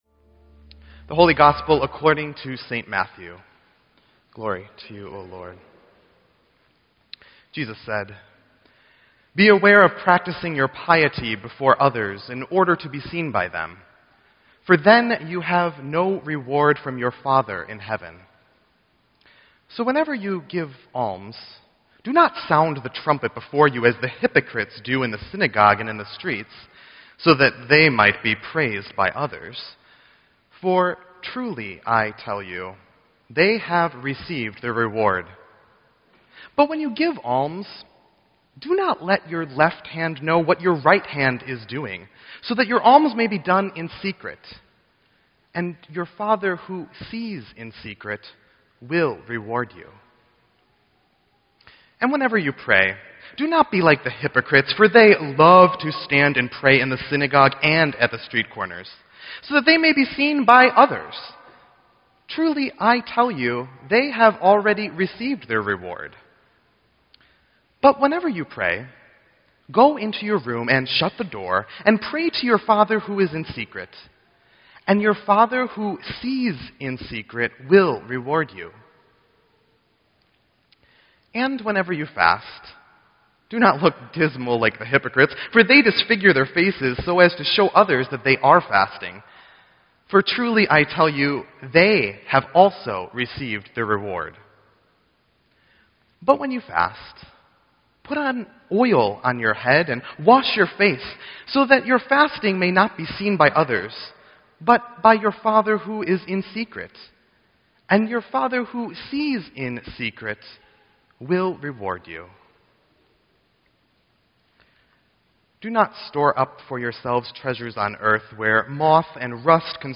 Ash Wednesday-Wicker Park Lutheran Church 2/10/16
Sermon_2_10_16.mp3